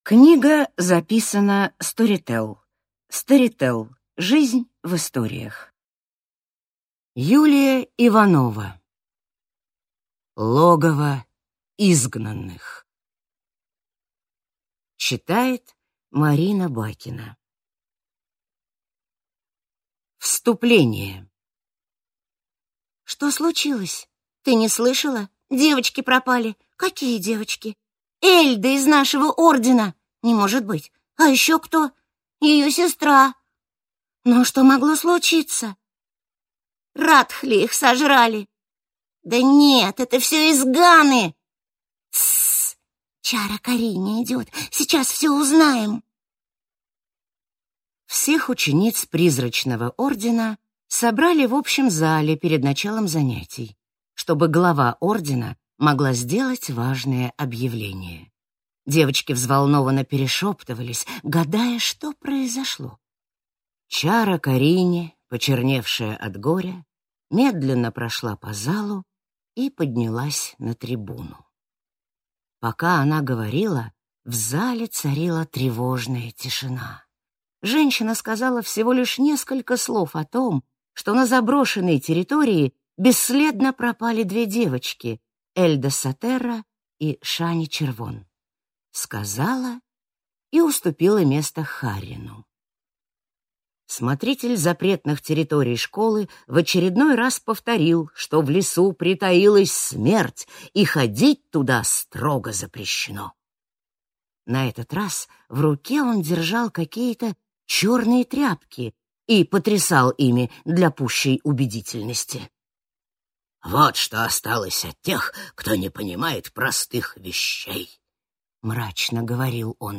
Аудиокнига Тайны Чароводья. Логово изгнанных. Книга третья | Библиотека аудиокниг